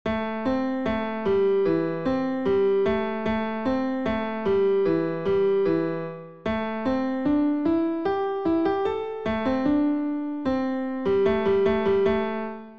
The audio files on this page are simple piano renditions of each leitmotif, using their "base forms" that are iterated upon in various tracks.
The Forest leitmotif is an old melody that typically is used in forest music, especially that which is for a secluded and calm setting. It is comprised of slow natural notes somewhat sporadically progressing up and down, then rising before a gentle descent and returning to the starting note.
Motif_forest.mp3